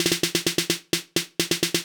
INT Beat - Mix 2.wav